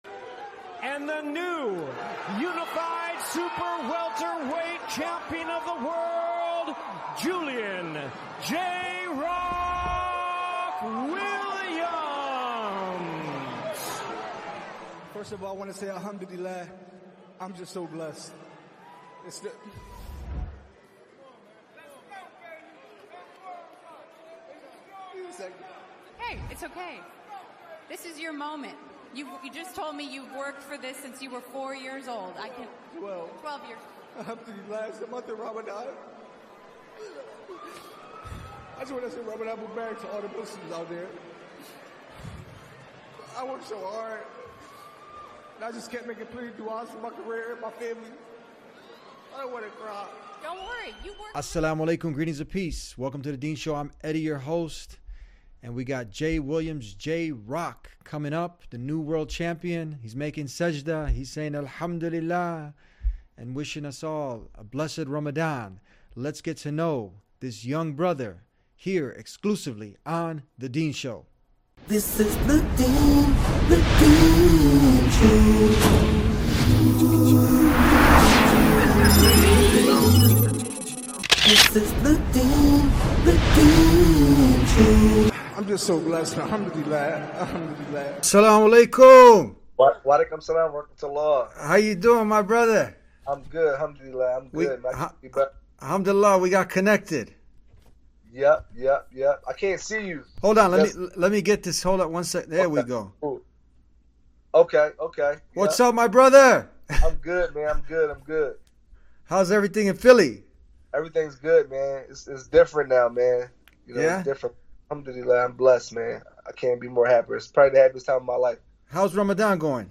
In an exclusive interview on The Deen Show, Julian “J-Rock” Williams, the WBA, IBF, and IBO super welterweight champion, opened up about his deep connection to Islam, the blessings of Ramadan, and why saying Alhamdulillah was the only response that felt right in the greatest moment of his life.